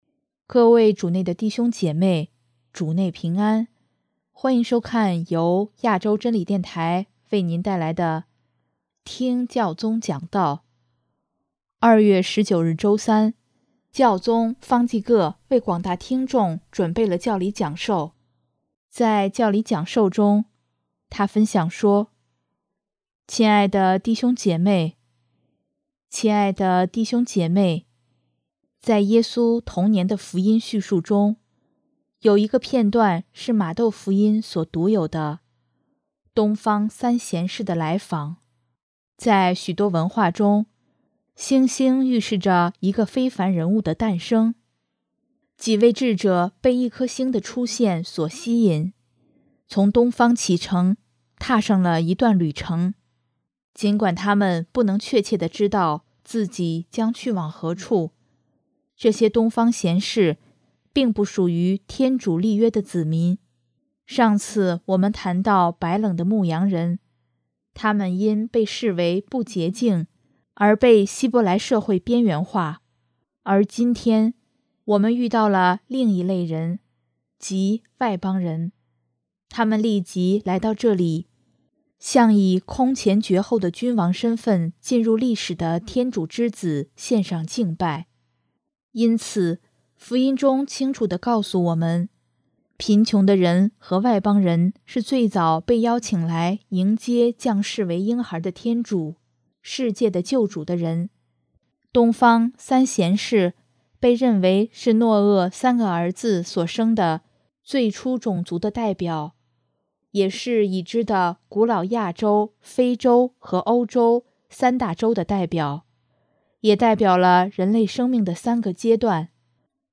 2月19日周三，教宗方济各为广大听众准备教理讲授，在教理讲授中，他分享说：